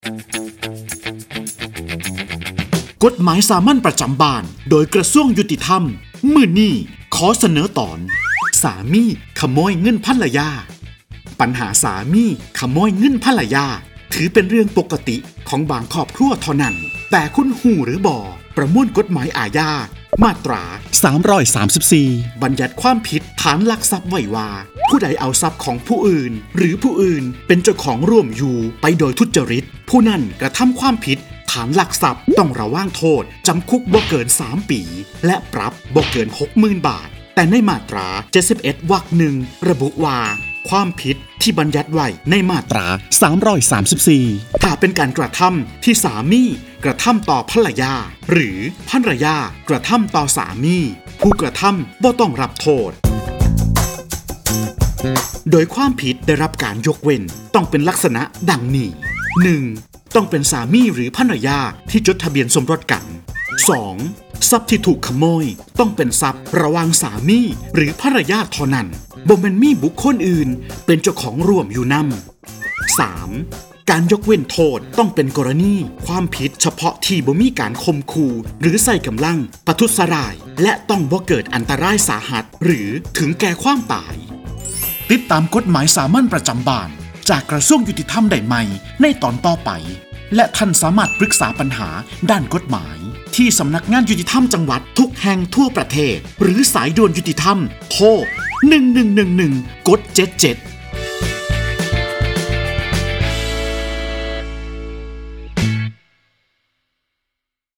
กฎหมายสามัญประจำบ้าน ฉบับภาษาท้องถิ่น ภาคอีสาน ตอนสามีขโมยเงินภรรยา
ลักษณะของสื่อ :   คลิปเสียง, บรรยาย